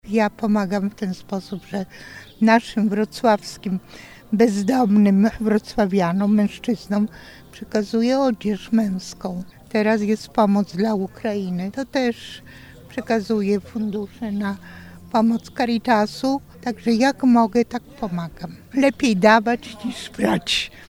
W sobotę o godzinie 10:00 rozpoczął się Zjazd Parafialnych Zespołów Caritas Archidiecezji Wrocławskiej.
– Warto pomagać potrzebującym – mówiła jedna z wolontariuszek.